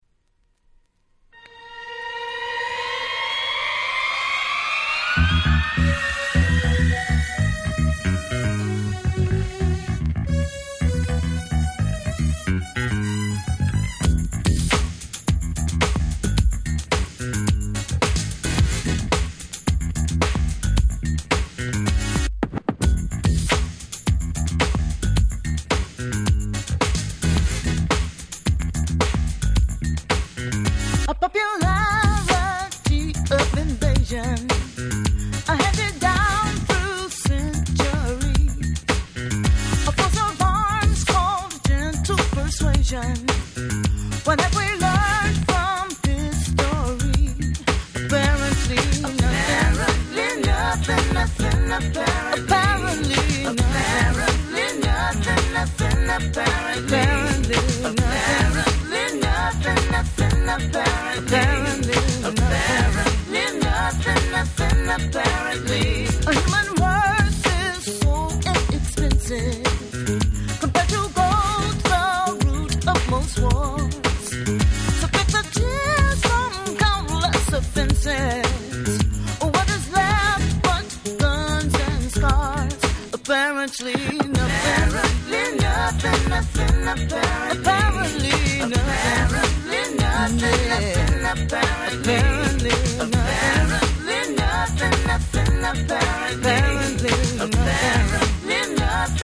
99' Nice Acid Jazz !!